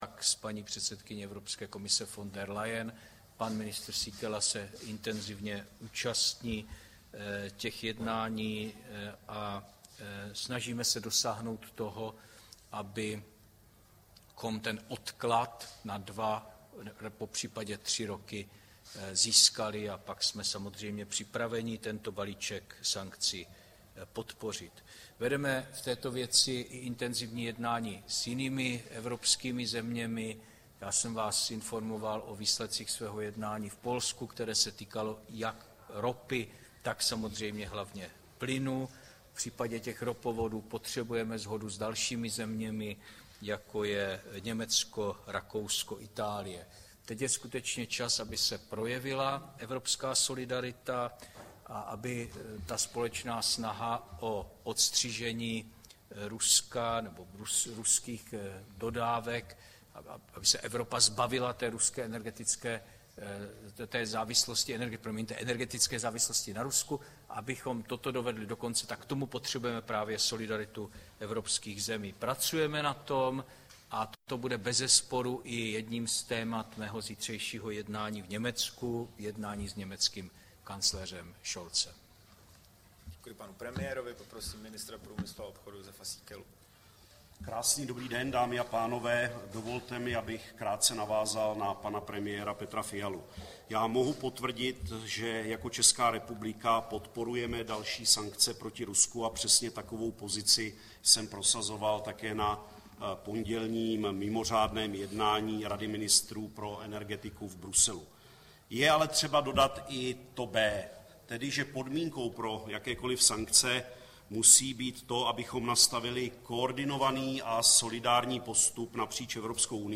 Tisková konference po jednání vlády, 4. května 2022